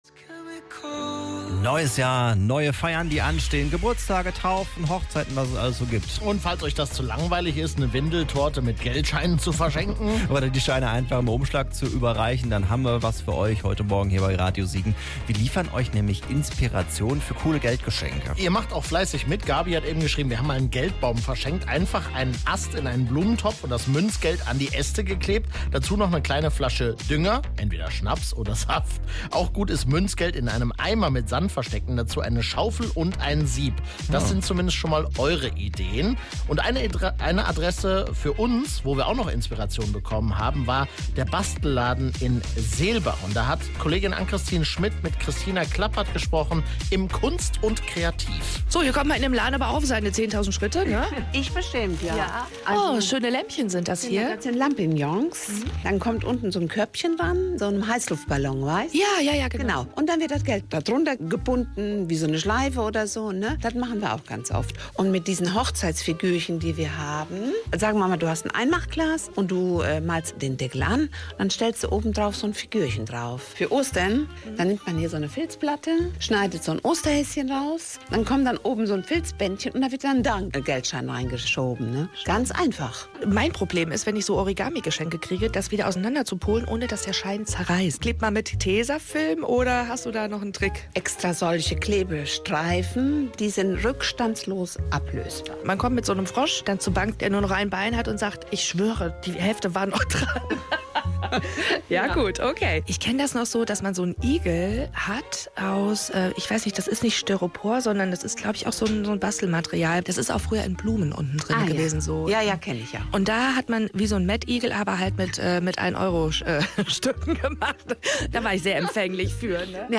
Weitere Tipps gibts im zweiten Teil des Interviews
Bastelladen Interview Teil 2